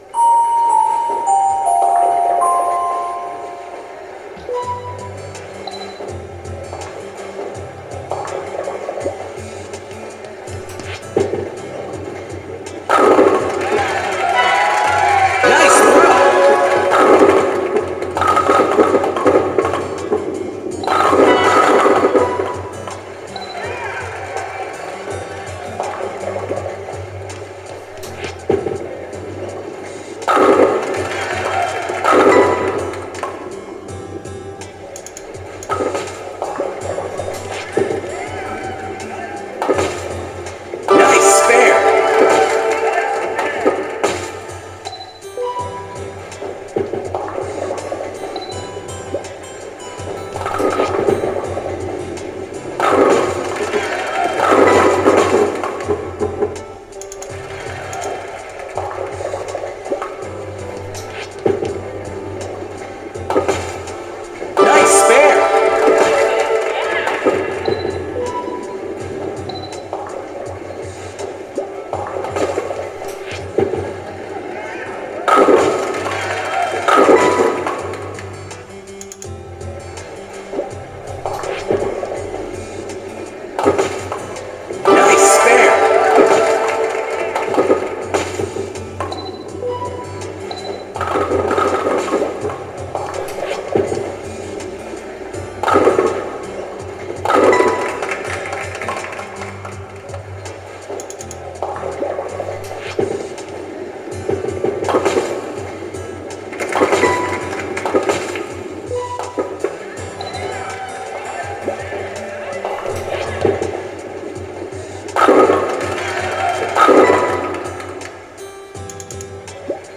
Se facciamo strike l’arbitro si congratula dicendo “Nice strike!” o se facciamo spare “Nice spare!”, altrimenti nulla, ci riproviamo al tiro successivo.
Eccovi la mia partita di bowling.